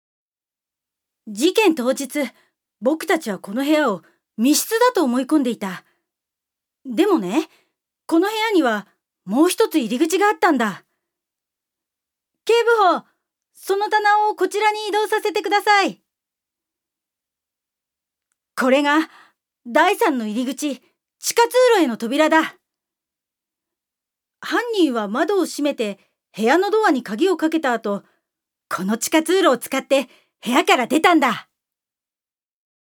ジュニア：女性
セリフ２